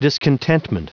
Prononciation du mot discontentment en anglais (fichier audio)
Prononciation du mot : discontentment